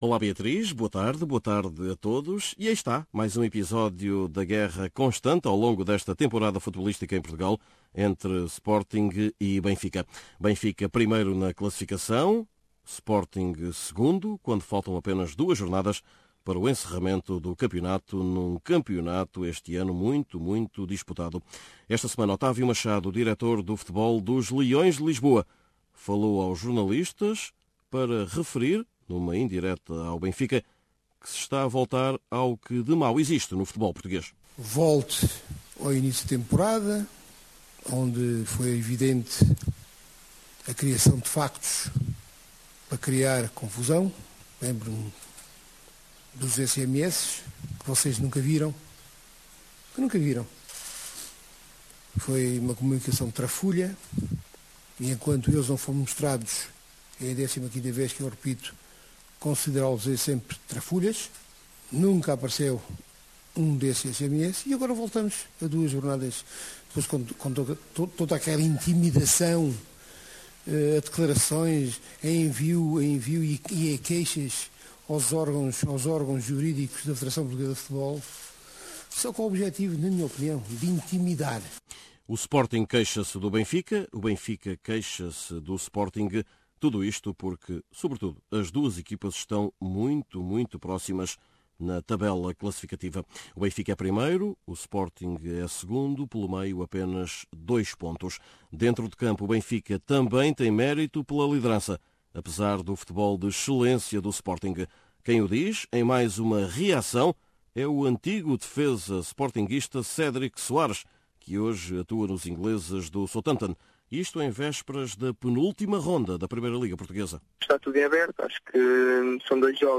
Neste boletim semanal